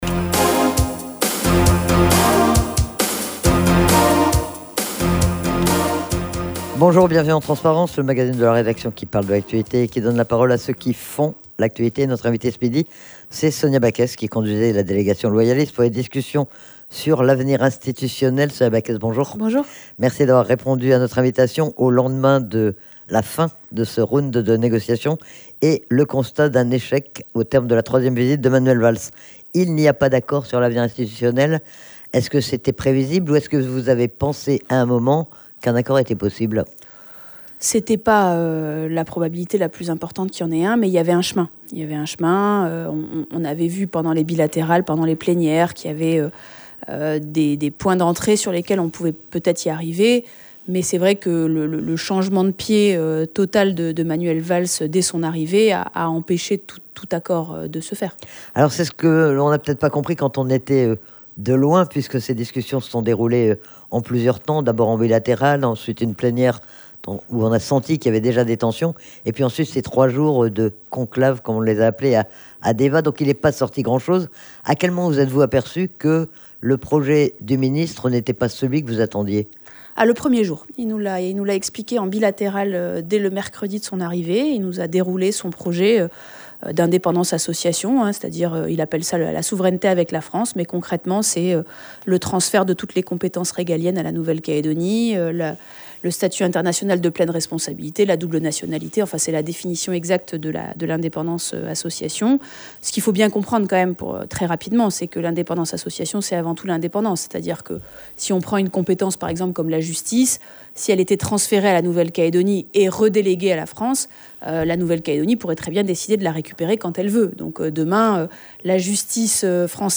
Nous sommes revenus, ce midi, sur l'échec des discussions et l'absence d'un accord au terme du déplacement de Manuel Valls. Sonia Backès était l'invitée